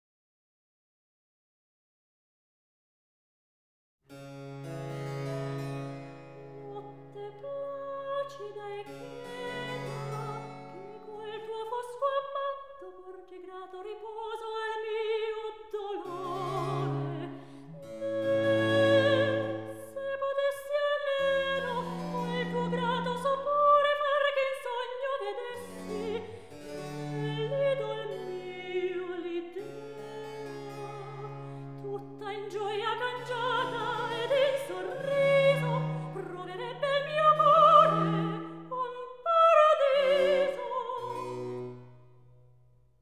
Recitativo